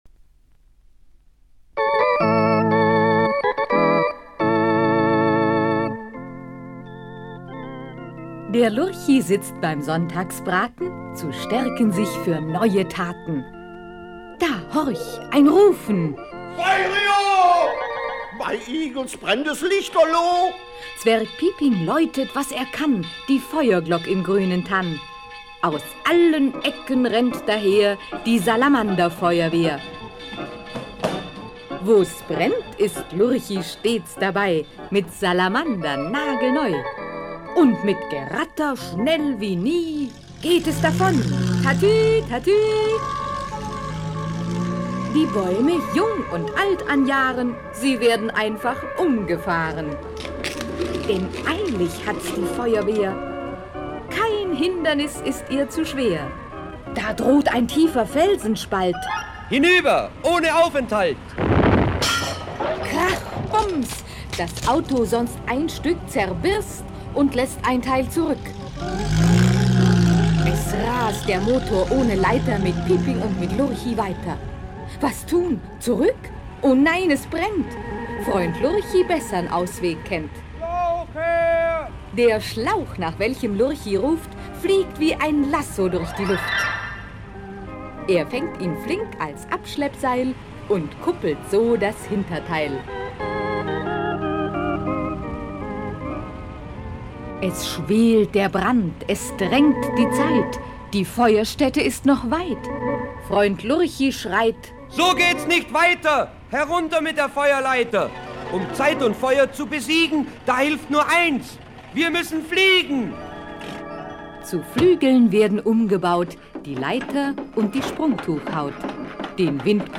Lurchis Hörspiele
Lurchis Schallplatten